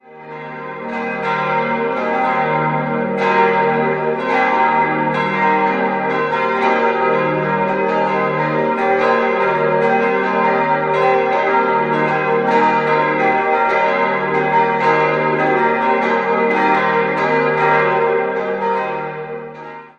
4-stimmiges Wachet-auf-Geläute: es'-g'-b'-c'' Die Glocken wurden im Jahr 1992 von der Firma Bachert in Bad Friedrichshall gegossen.